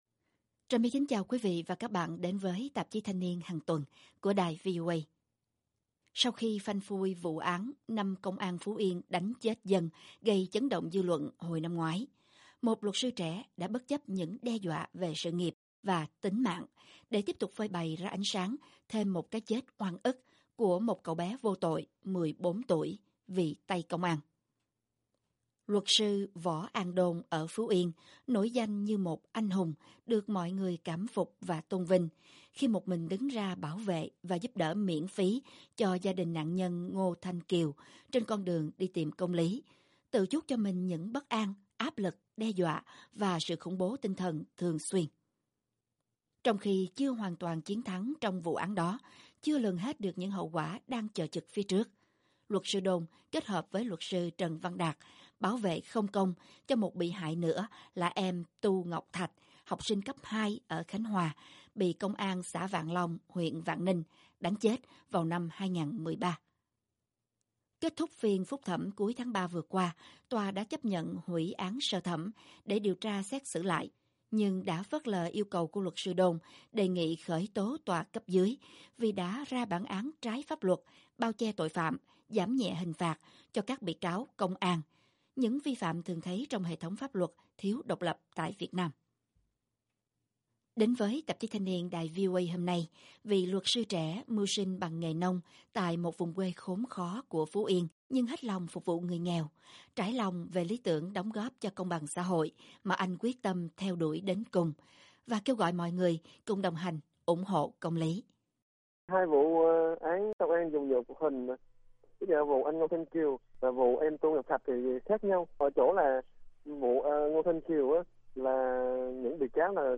Tạp chí Thanh niên - Phỏng vấn